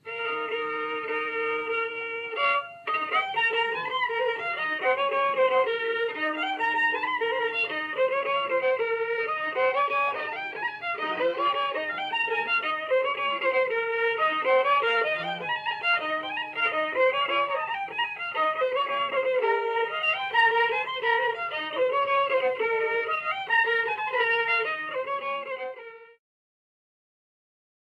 Tę wypowiedź przyjęliśmy jako motto dla płyty prezentującej najstarsze archiwalne nagrania ludowej muzyki skrzypcowej ze zbiorów Instytutu Sztuki.
17. Oberek